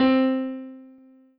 piano-ff-40.wav